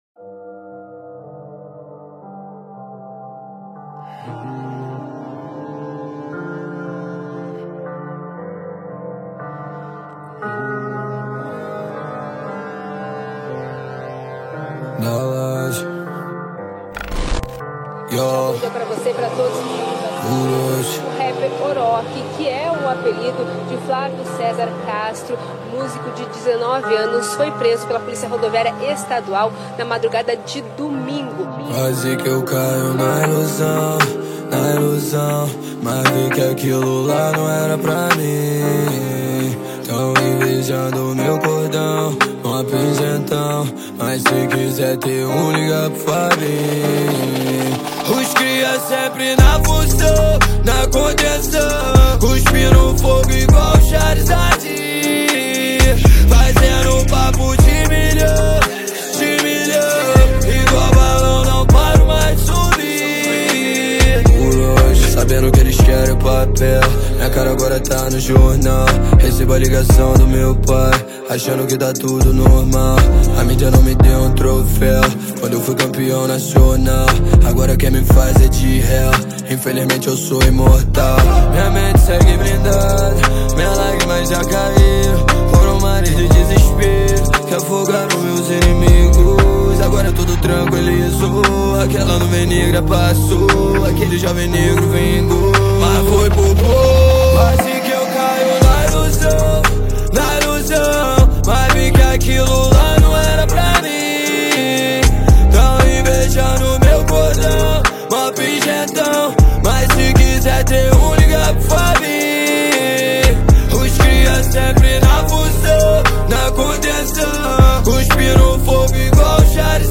2024-02-18 16:58:32 Gênero: Rap Views